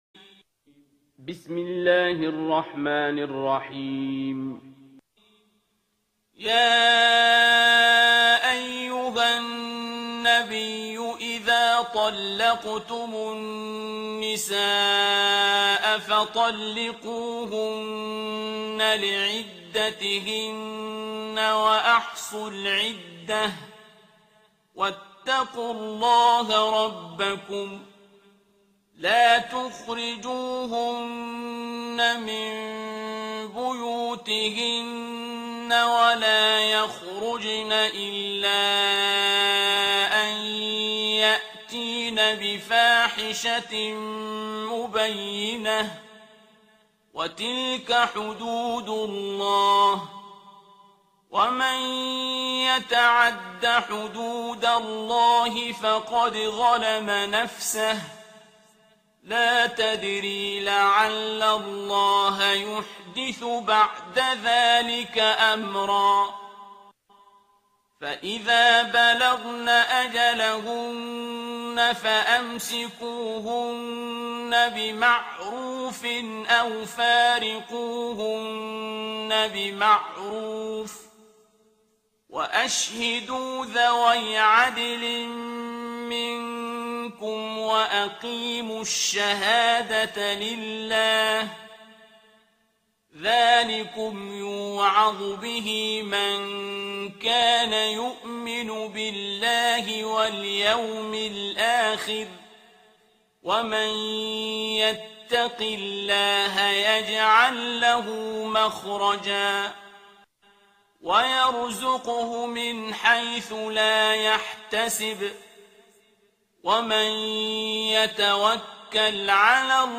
ترتیل سوره طلاق با صدای عبدالباسط عبدالصمد
065-Abdul-Basit-Surah-At-Talaq.mp3